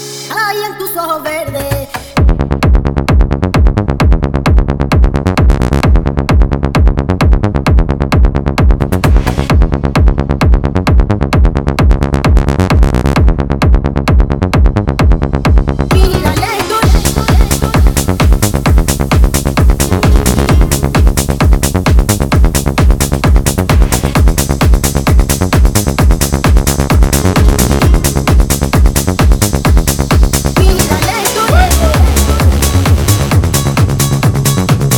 Techno Dance
Жанр: Танцевальные / Техно